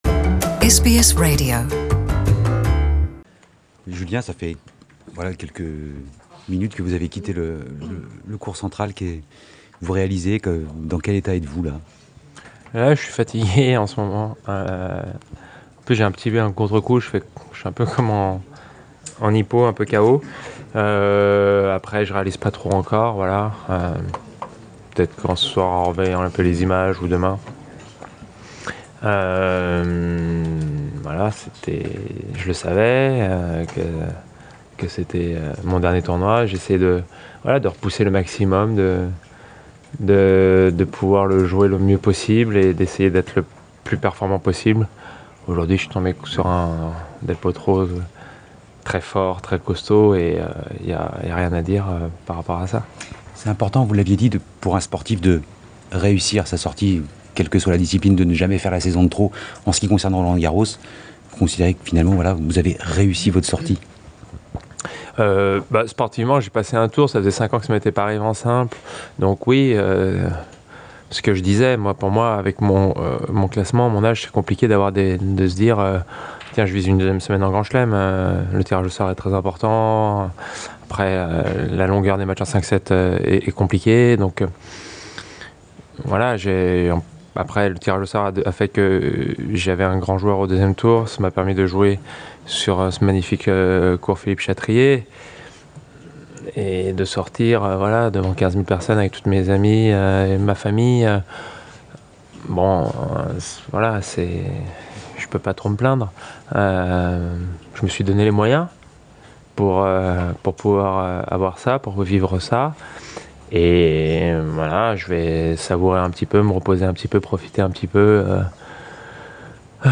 Son dernier match à Roland-Garros a été marqué par une défaite contre Juan del Potro. Il nous fait part de sa réaction après le match.